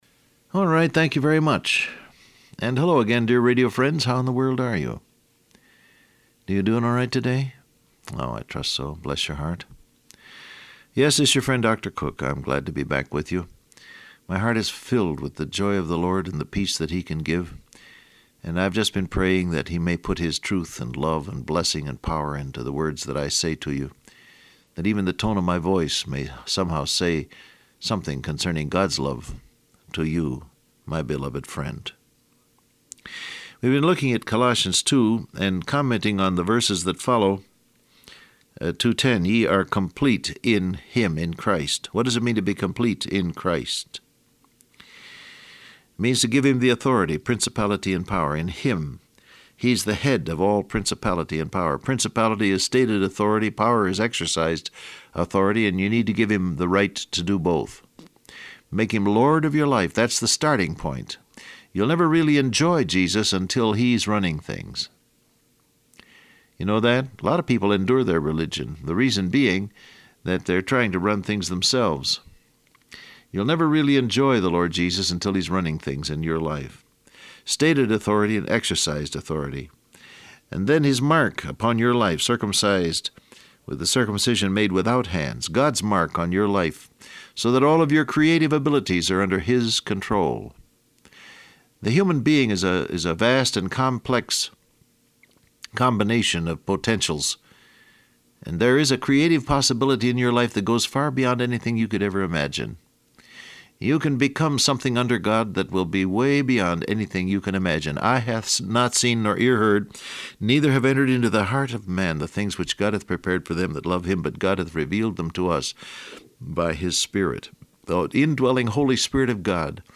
Download Audio Print Broadcast #1891 Scripture: Colossians 2:10-15 , Ephesians 2:1, John 5:25, Romans 8 Transcript Facebook Twitter WhatsApp Alright, thank you very much.